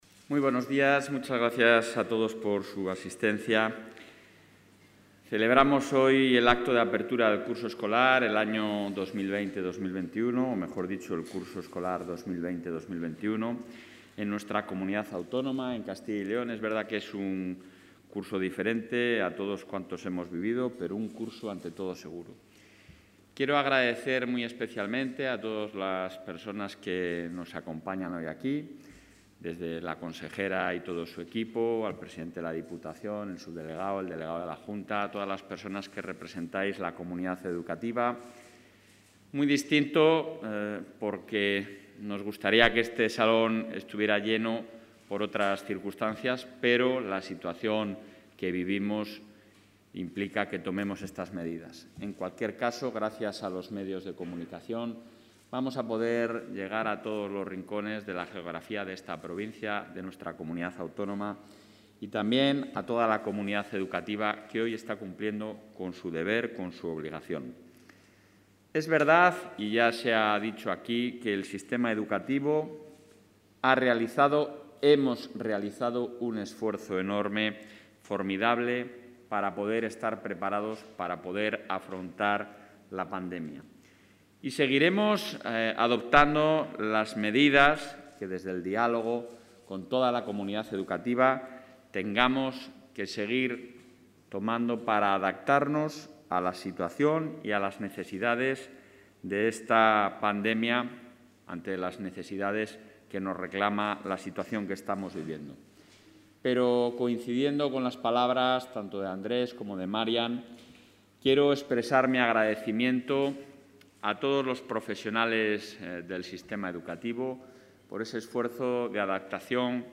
Intervención del presidente de la Junta de Castilla y León.